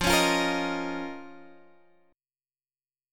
Listen to F7sus4#5 strummed